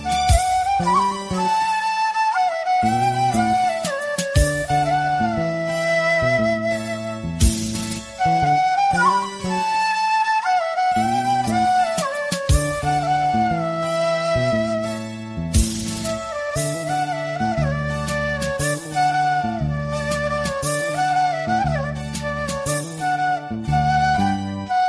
Flute Ringtone For Android and iPhone mobiles.